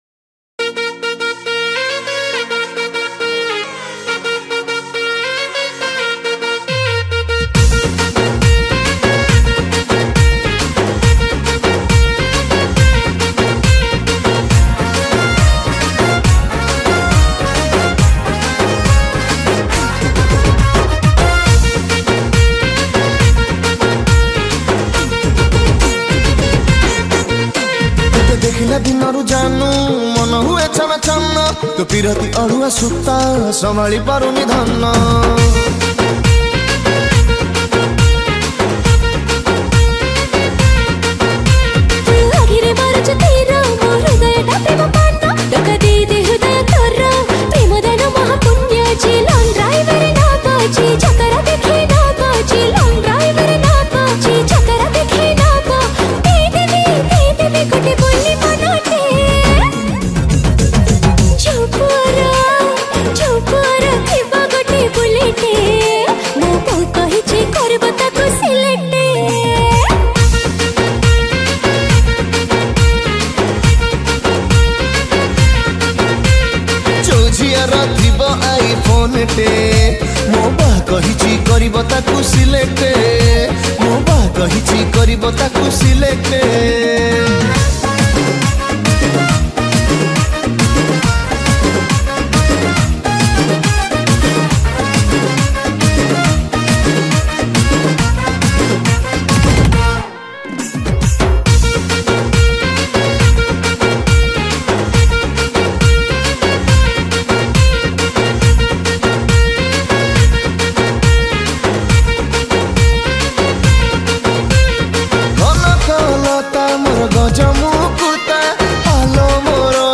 Key Board